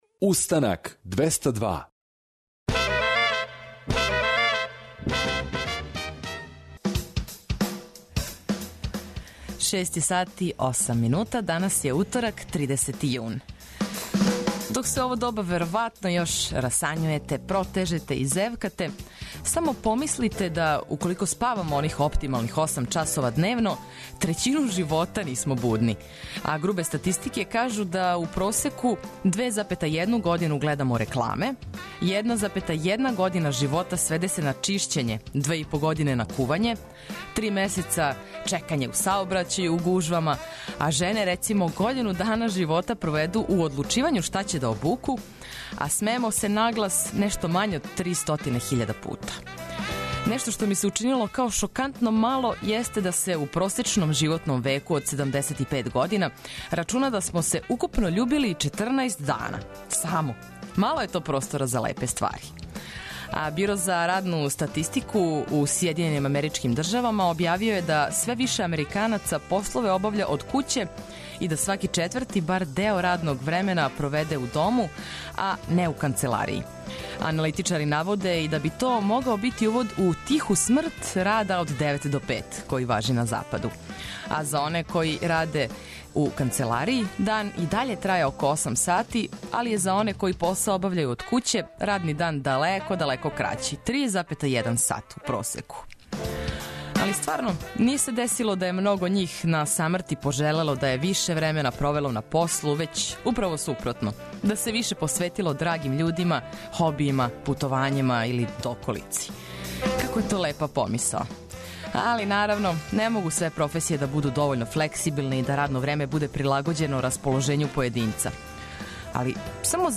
Разбуђивање од 6 ујутру је стандард, све корисне информације и добра музика за почетак дана су ту као база, а летњи Устанак вам представља и мале али значајне измене у садржају!